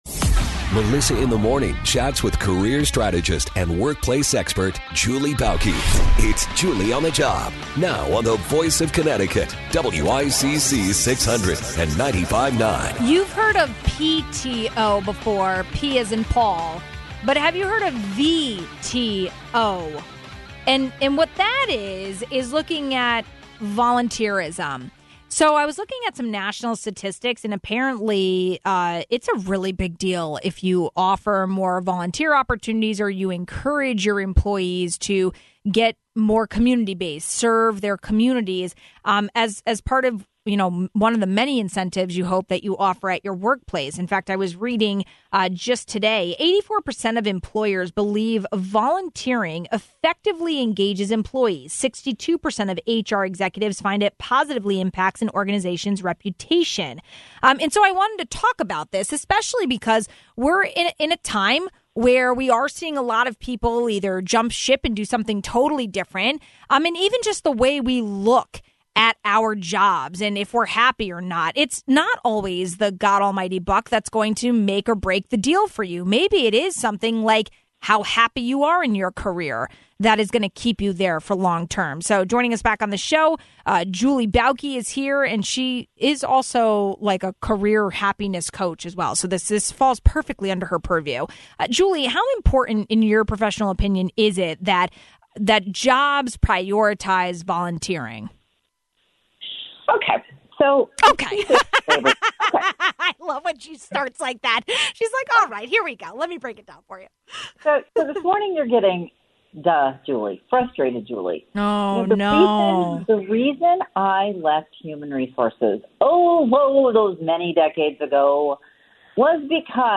National statistics prove more and more workers want volunteerism at the workplace to be prioritized. But what does that look like and how do leaders incorporate this into the office culture if it’s not already there? We asked career strategist